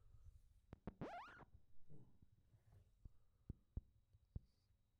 Проблема с микрофоном Audio-Technica AT4047/SV
Здравствуйте, подскажите пожалуйста началась проблема с микрофоном AT4047/SV, рандомно издает странные звуки, запись прикрепляю, в чем может быть проблема?